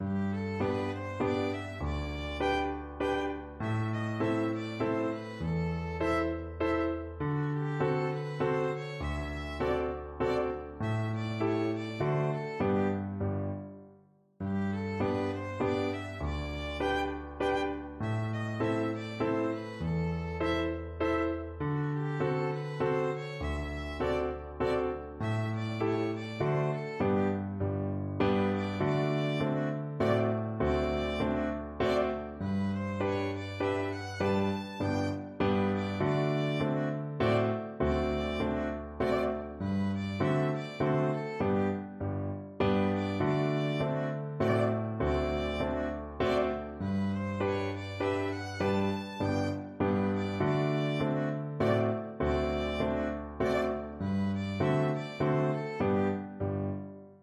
3/4 (View more 3/4 Music)
Arrangement for Violin and Piano
Classical (View more Classical Violin Music)